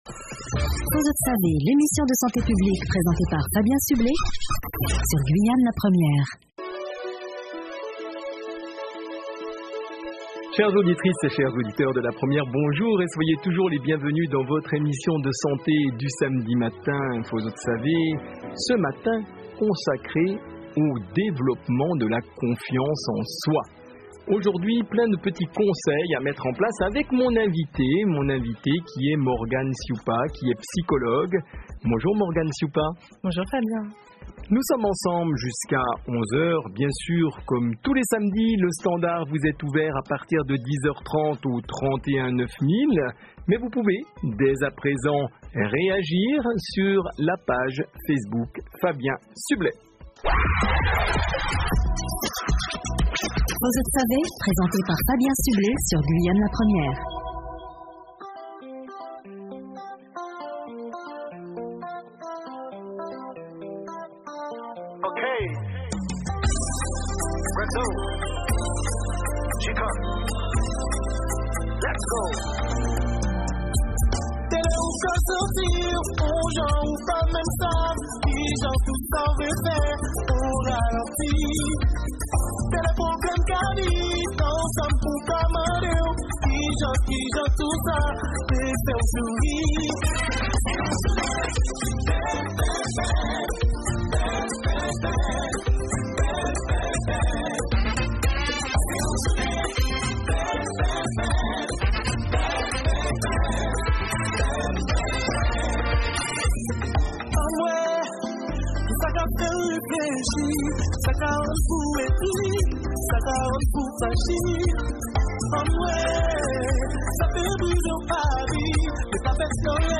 (int erview que j'ai faite pour une émission de radio) Gérer son stress De quoi dépend le bonheur ? 5 applications pour mieux dormir Qu’est-ce que la méditation ?